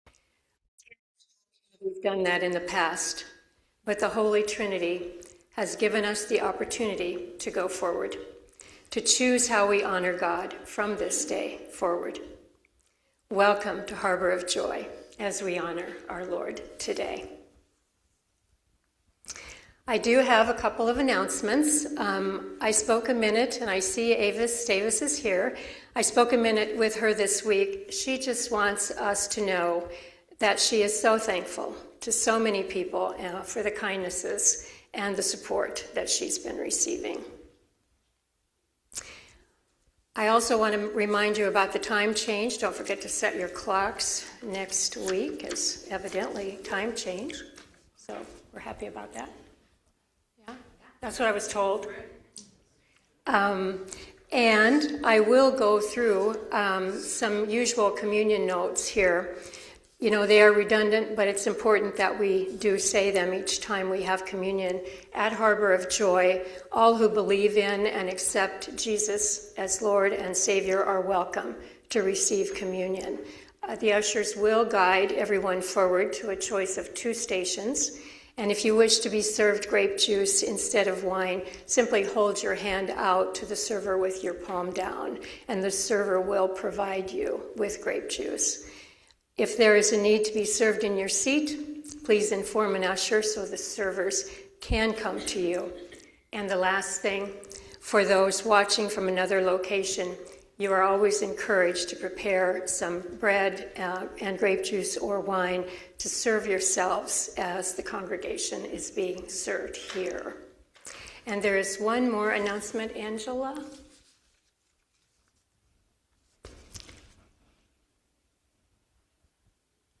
Worship-March-1-2026-Voice-Only.mp3